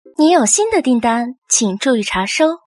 手机订单到了后的提示音.MP3